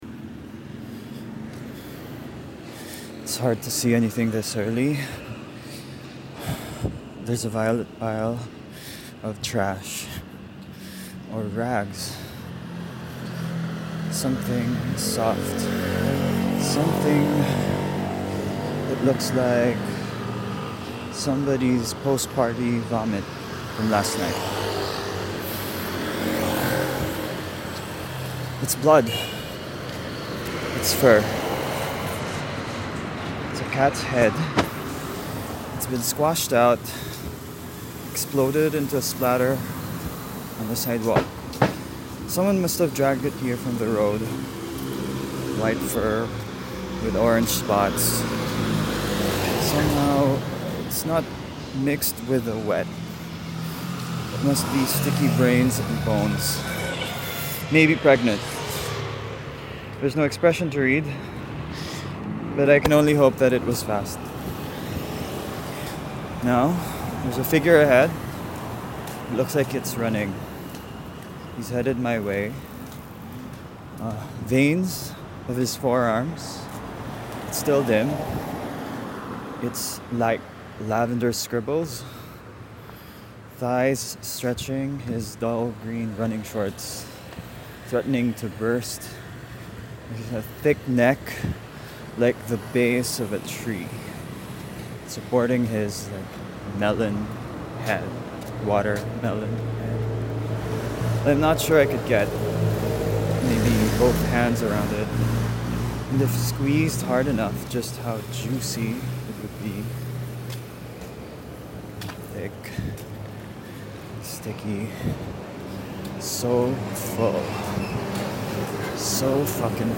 field recording spoken word